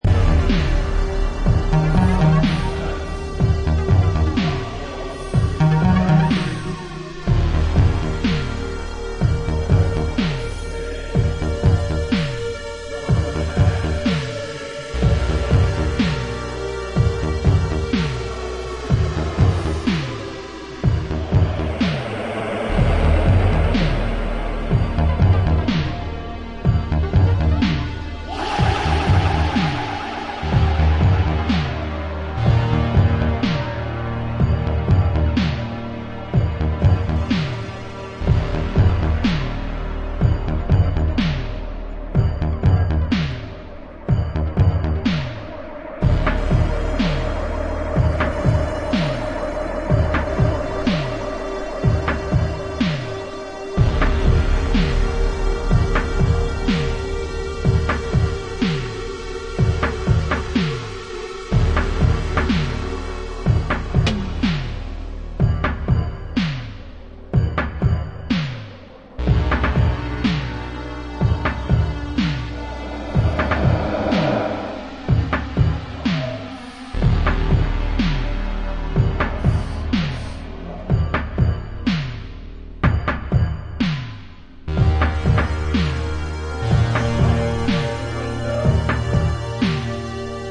EBM
funky-industrial mixture
Electro Electronix